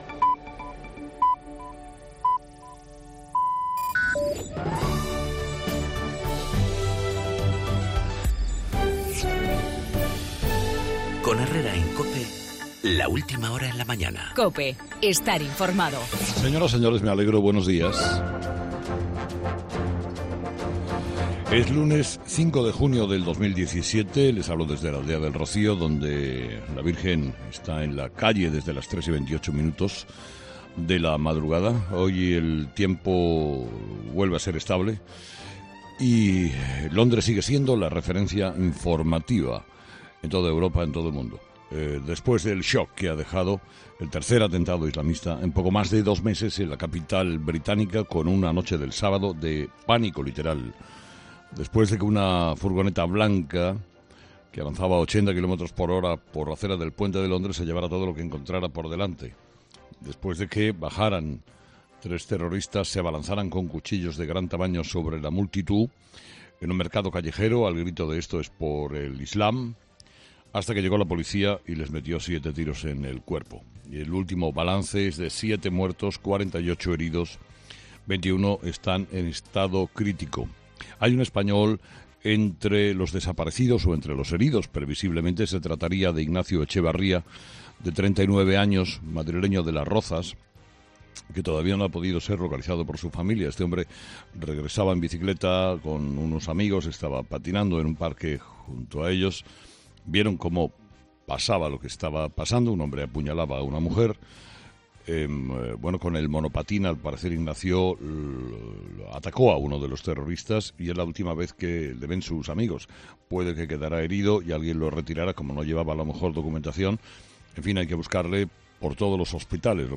AUDIO: El atentado en Londres del pasado sábado, en el monólogo de Carlos Herrera a las 8 de la mañana.